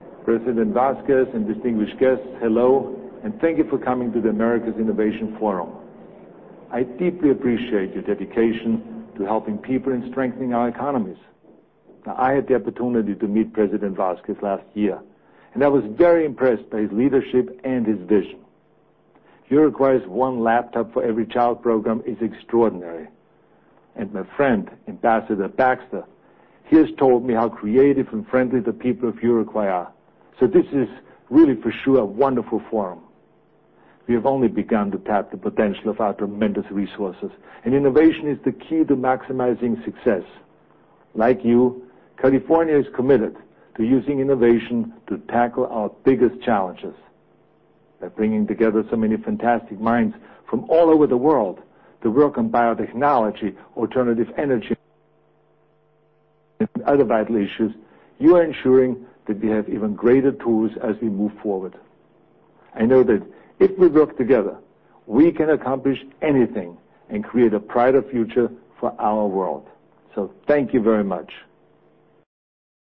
Aunque no estuvo presente, el gobernador de California, Arnold Schwarzenegger envió un saludo a los participantes del foro